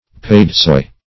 Search Result for " padesoy" : The Collaborative International Dictionary of English v.0.48: Padesoy \Pad"e*soy`\, n. See Paduasoy .